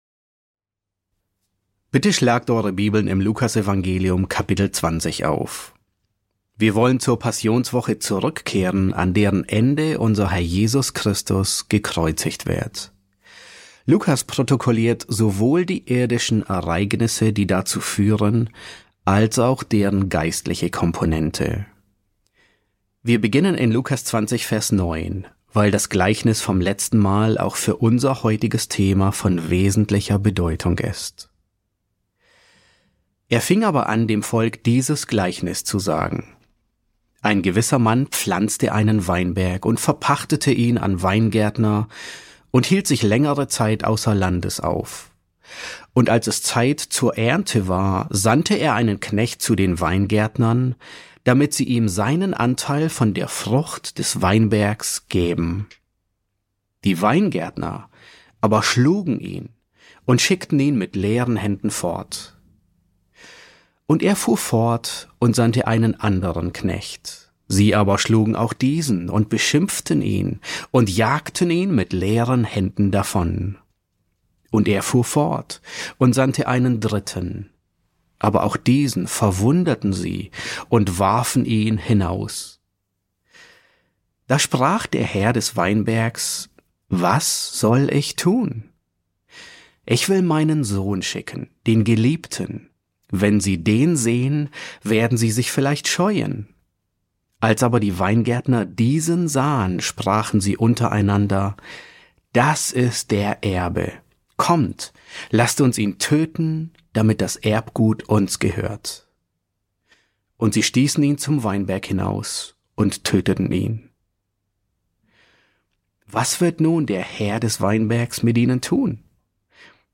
E5 S7 | Eine Diagnose derer, die Christus ablehnen ~ John MacArthur Predigten auf Deutsch Podcast